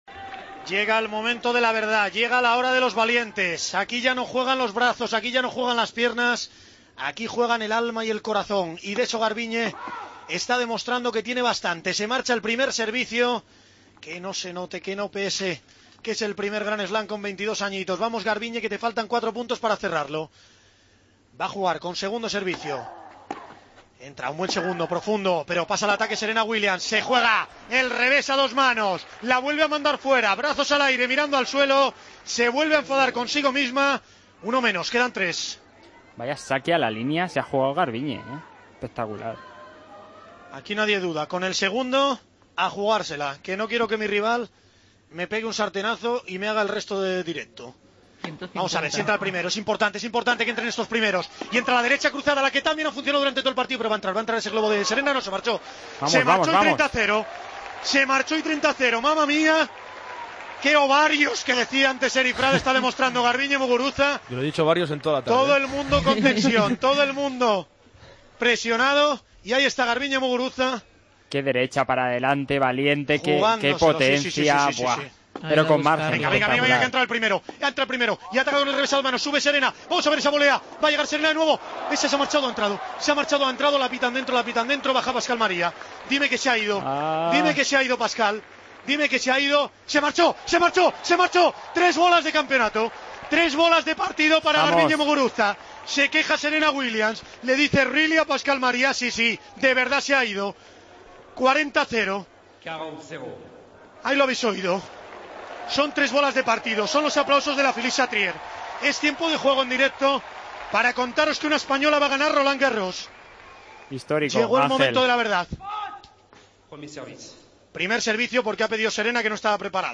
narró desde París la victoria de Garbiñe Muguruza en Roland Garros ante Serena Williams. Este fue el punto final.